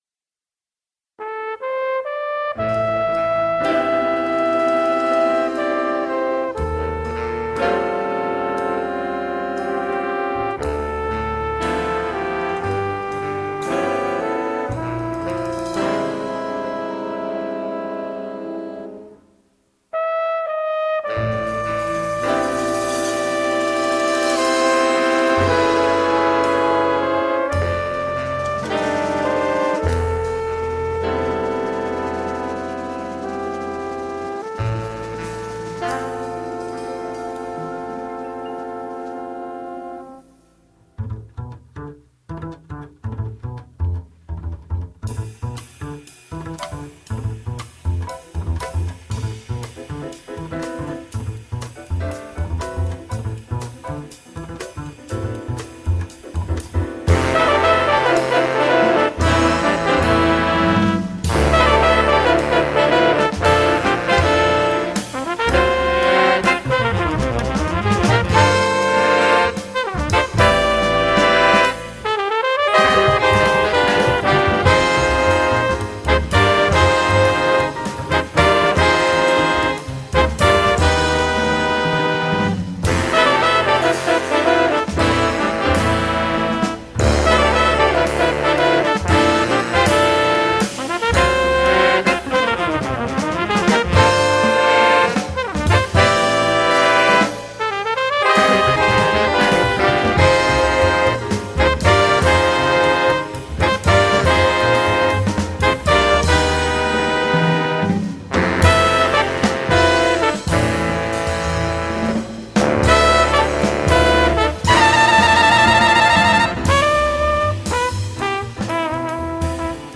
Voicing: Combo 7+